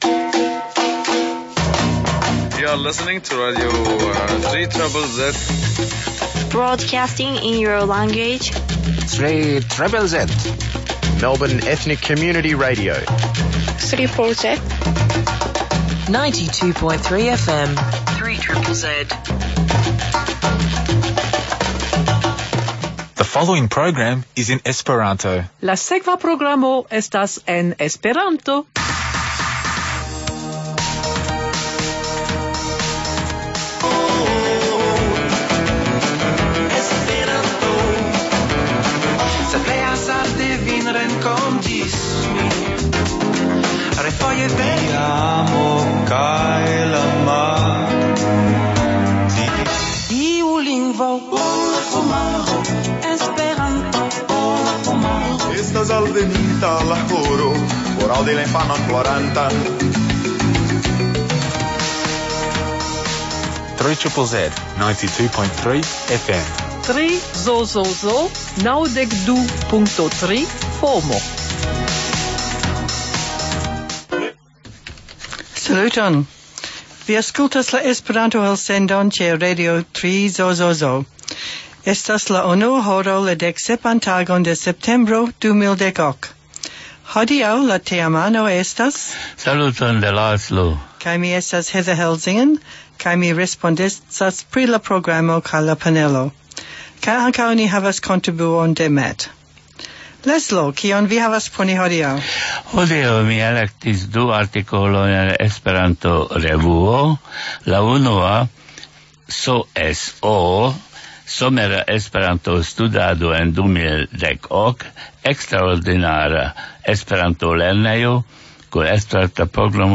Kanto
Legado